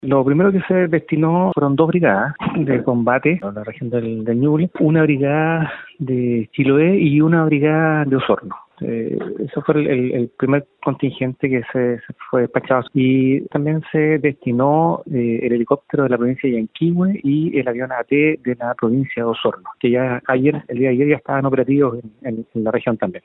El director de Conaf en Los Lagos, Marco Inarejo, explicó que primero se designaron dos brigadas de combate a Ñuble, una de Chiloé y otra de Osorno; y posteriormente se destinaron recursos aéreos.